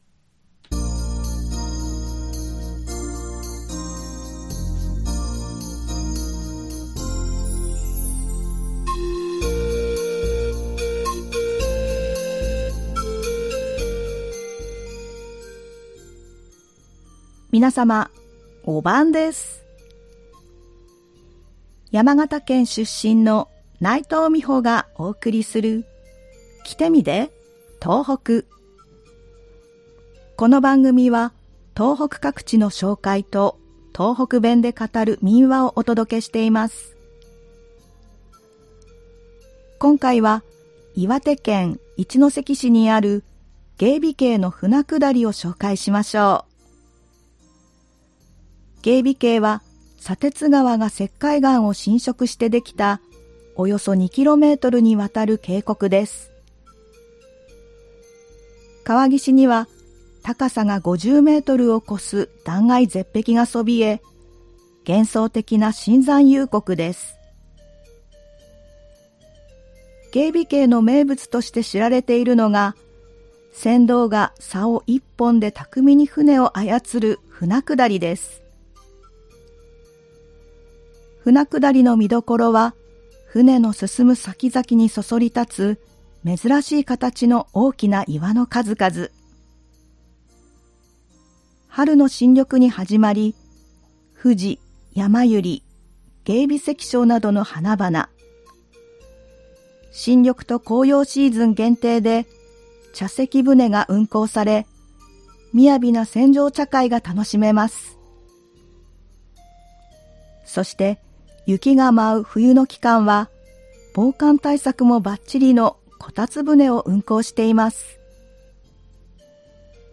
この番組は東北各地の紹介と、東北弁で語る民話をお届けしています（再生ボタン▶を押すと番組が始まります）
ではここから、東北弁で語る民話をお送りします。今回は岩手県で語られていた民話「きつねにだまされた爺さま」です。